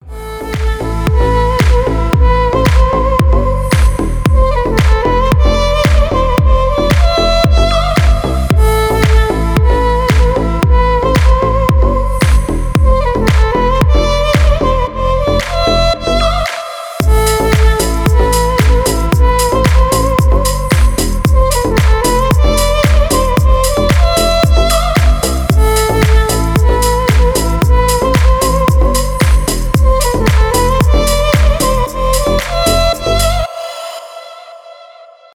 Рингтоны без слов
дудук , восточные , deep house